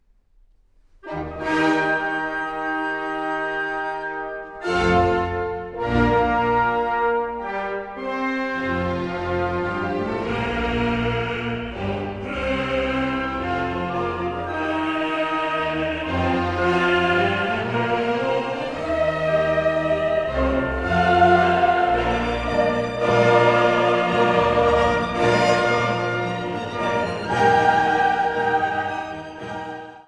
für vier Solostimmen, Chor und Orchester